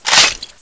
assets/psp/nzportable/nzp/sounds/weapons/browning/boltforward.wav at b9de79aab57e71dd025df5c62198b3720e460050
boltforward.wav